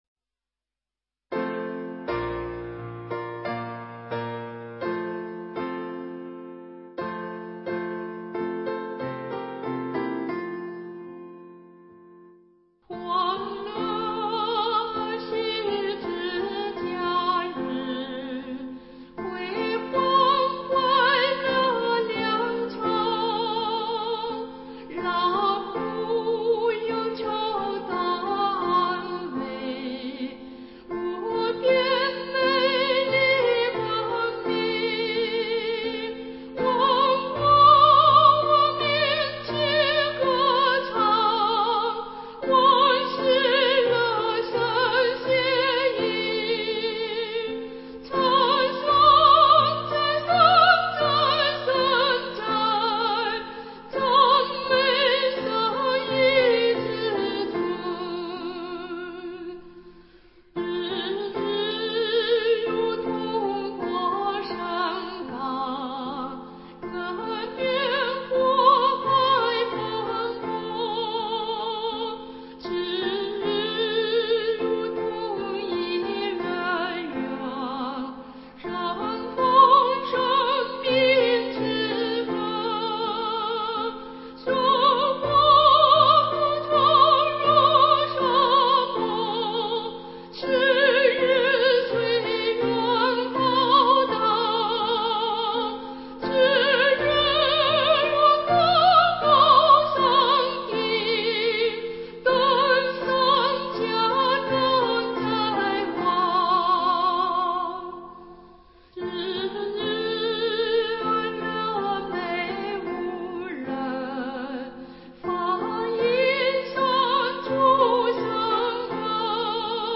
原唱